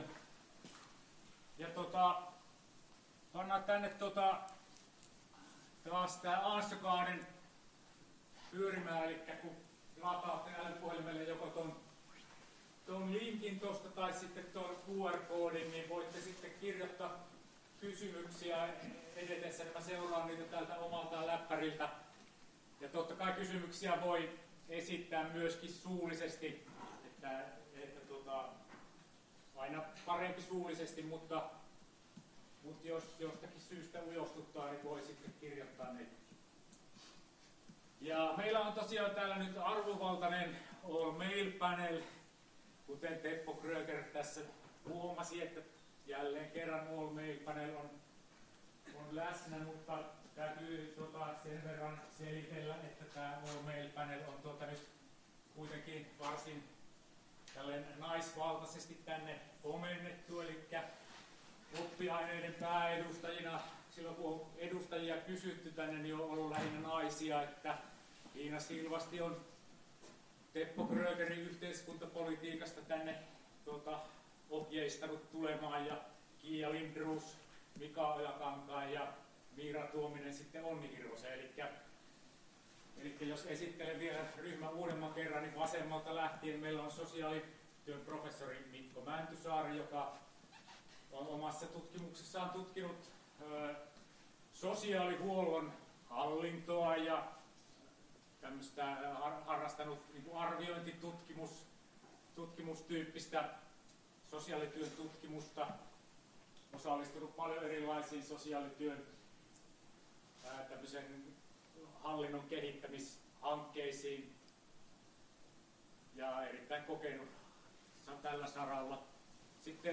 Luento 21.11.2018 — Moniviestin